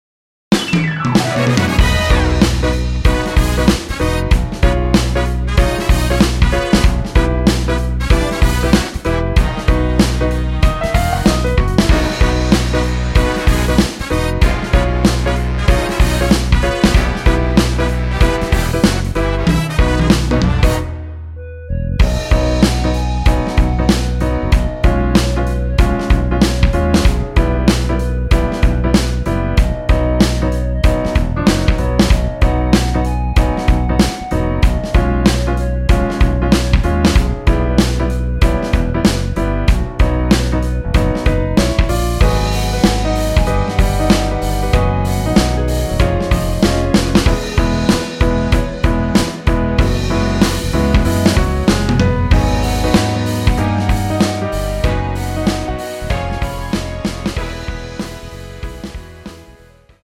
원곡보다 짧은 MR입니다.(아래 재생시간 확인)
원키에서(-3)내린 (1절앞+후렴)으로 진행되는 멜로디 포함된 MR입니다.
앞부분30초, 뒷부분30초씩 편집해서 올려 드리고 있습니다.
중간에 음이 끈어지고 다시 나오는 이유는